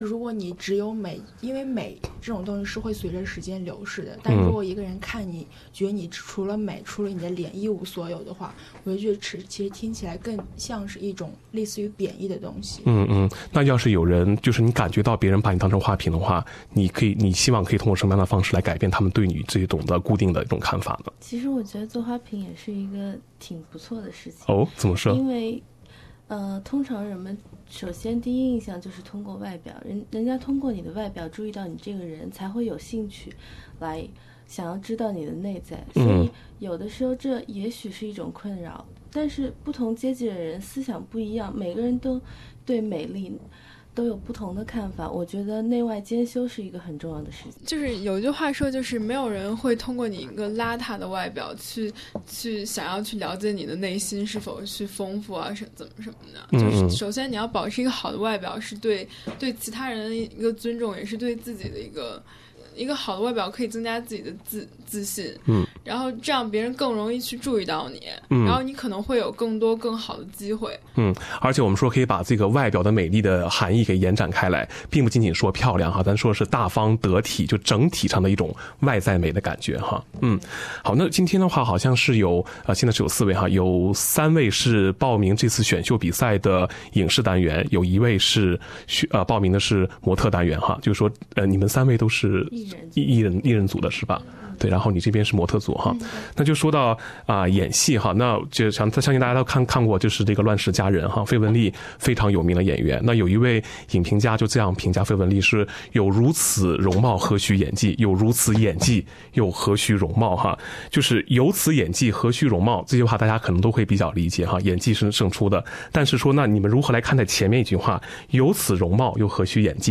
参加悉尼赛区的4位年轻女孩走进SBS直播间，分享自己对于美丽的理解。